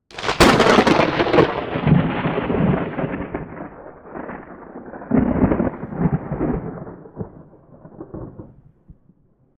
thunder_strike.ogg